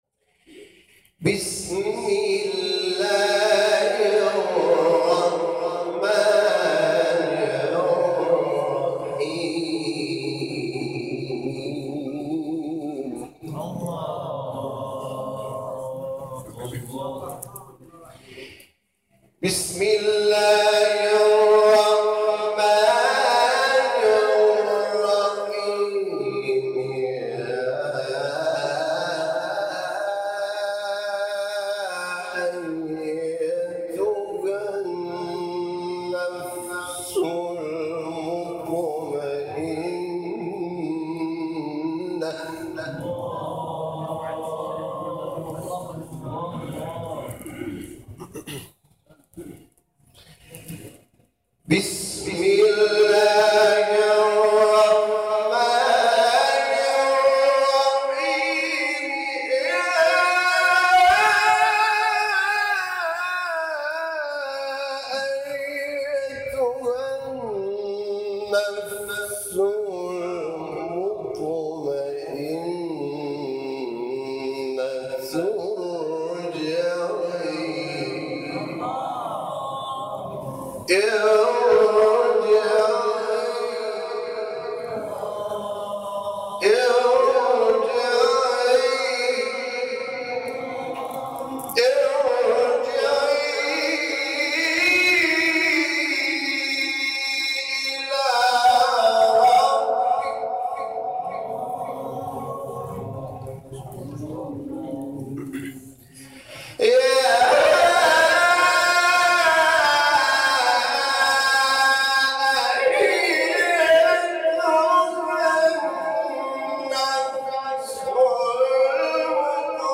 مقام بیات
دانلود تلاوت قرآن